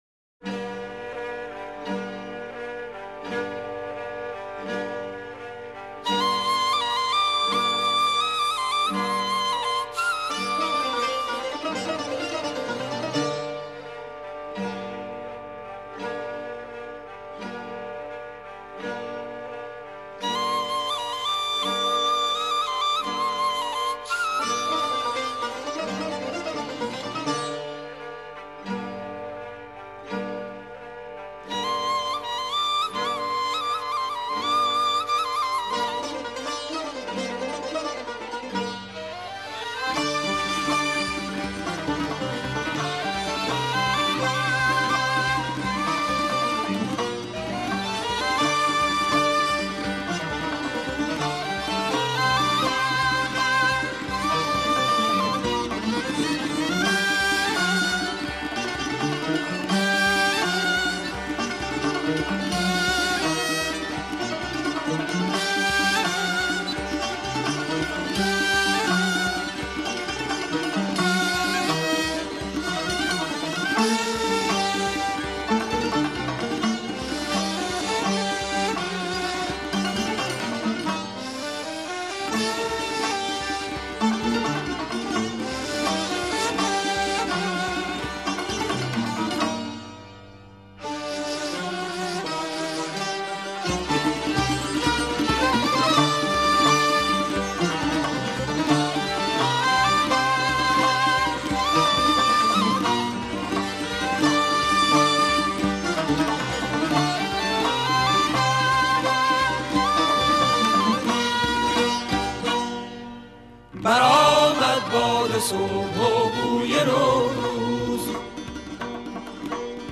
تصنیف